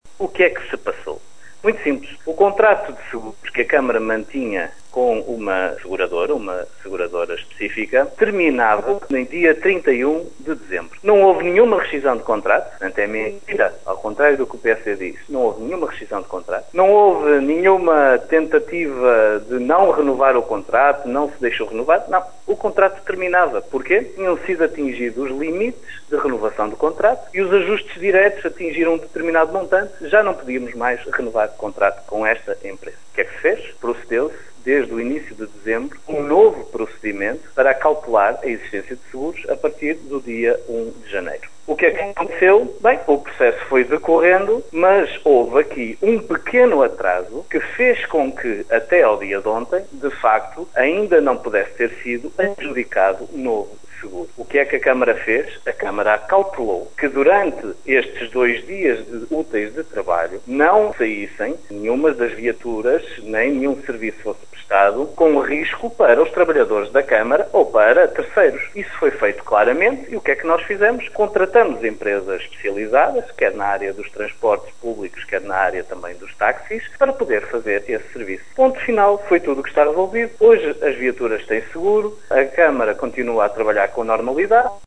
Em declarações à Rádio Caminha, Miguel Alves explicou a razão pela qual a autarquia esteve, pelo menos, durante dois dias úteis parada.